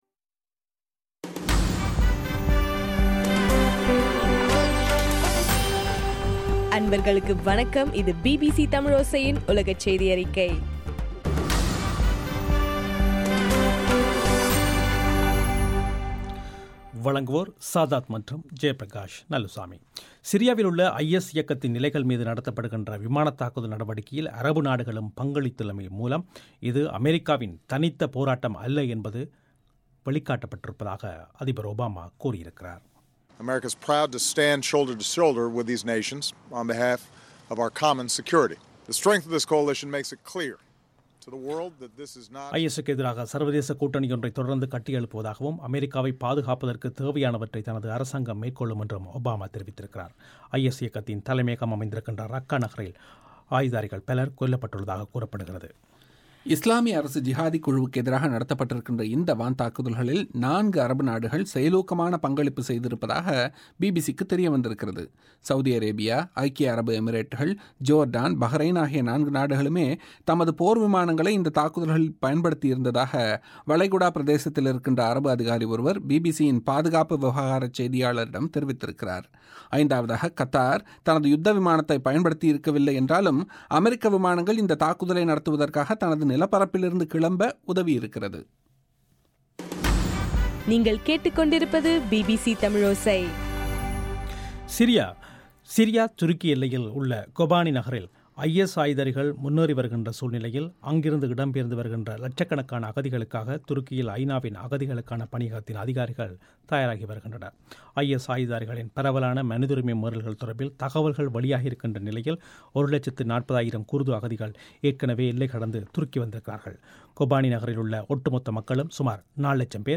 செப்டம்பர் 23 பிபிசியின் உலகச் செய்திகள்